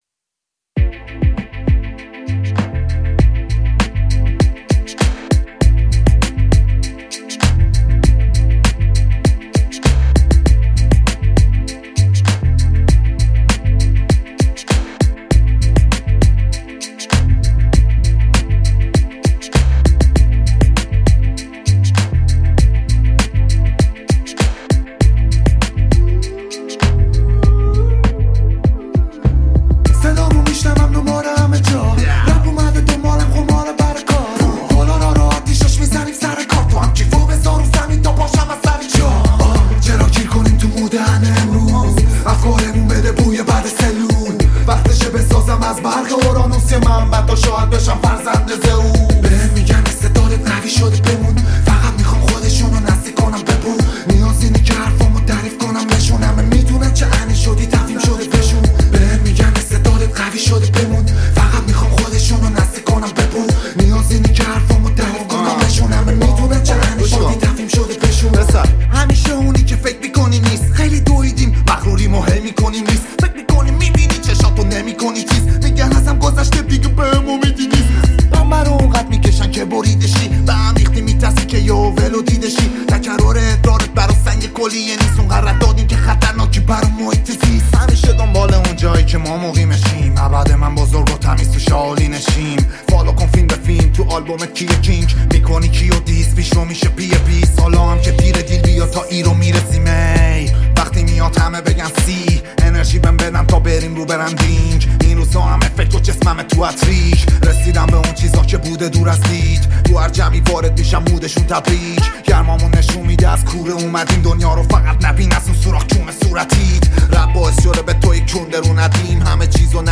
همه ی اخبار ها و حاشیه های رپ فارسی